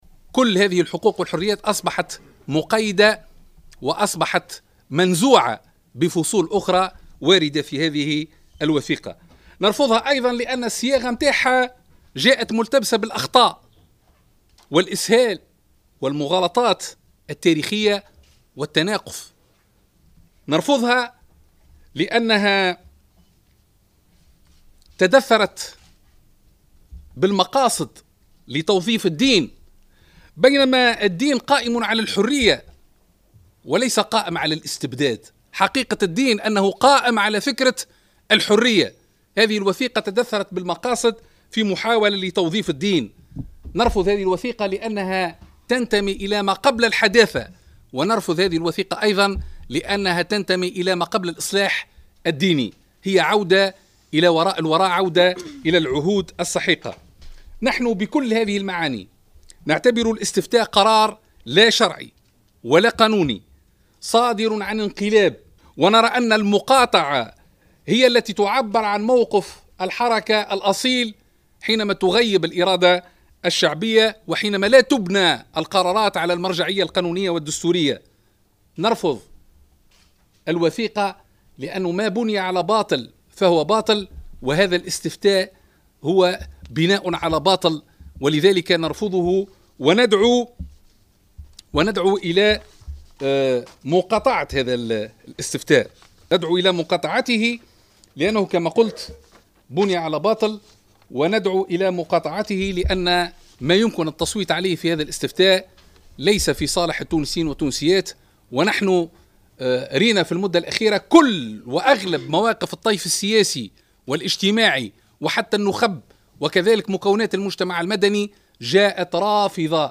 وأكد الخميري، خلال ندوة صحفية عقدتها الحركة، اليوم الخميس، أنه لا علاقة للنهضة ولا لرئيسها بقضيتي جمعية "نماء"، أو شركة "أنستالينغو".